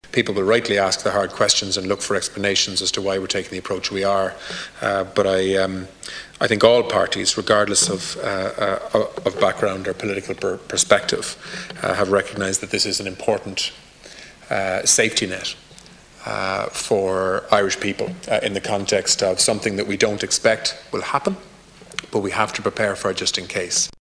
Tánaiste Simon Coveney says the new laws are an important back-up plan for Ireland: